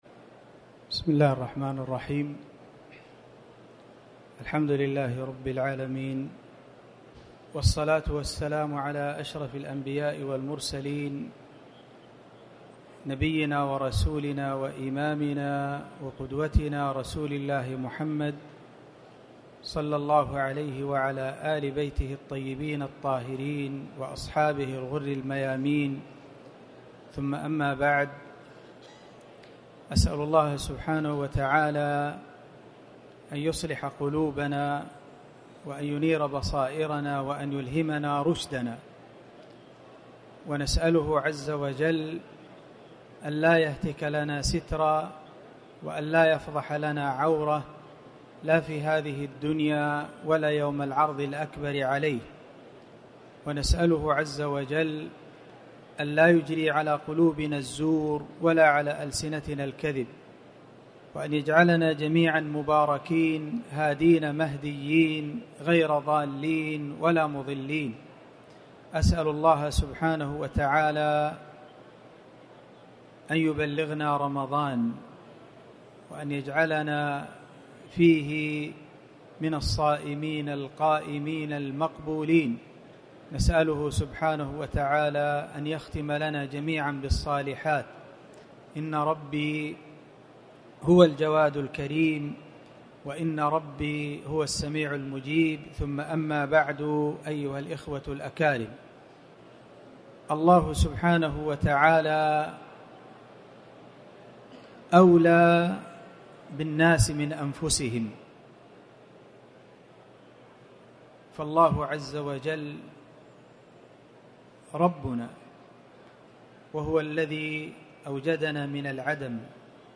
تاريخ النشر ٢١ شعبان ١٤٣٩ هـ المكان: المسجد الحرام الشيخ